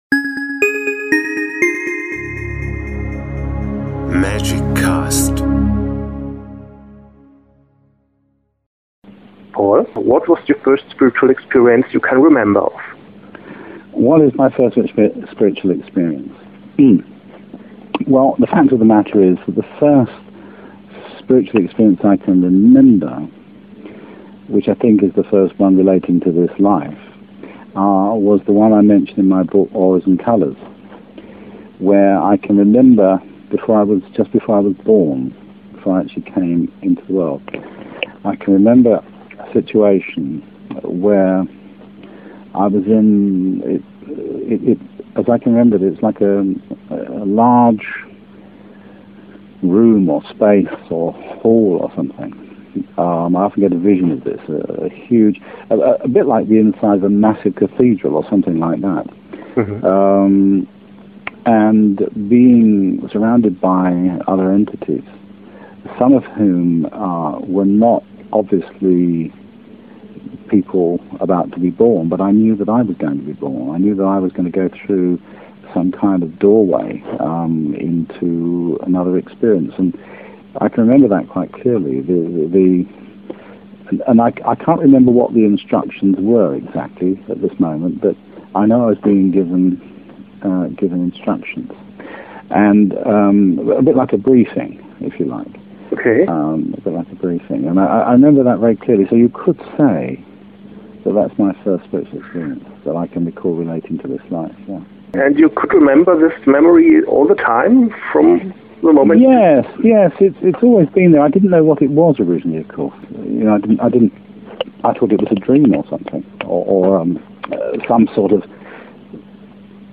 Interview
Englischer Originalton.